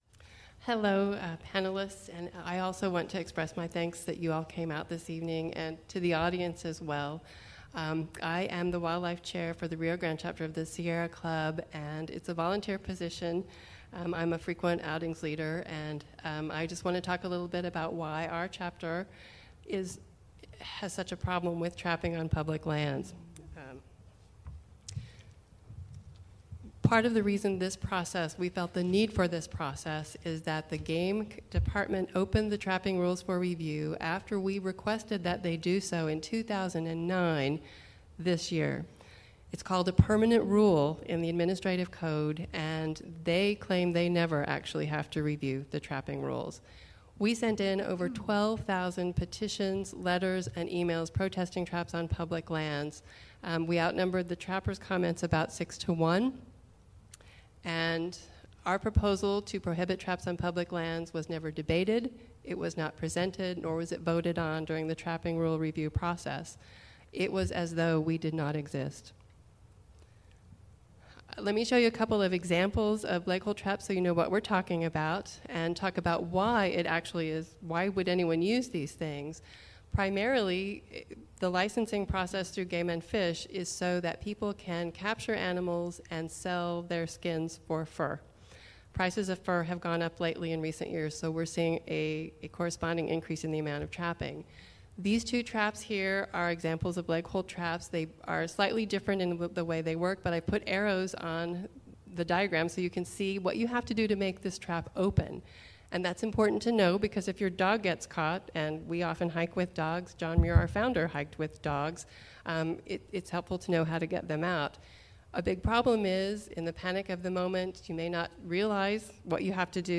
An audio recording was made of the proceedings of this public hearing.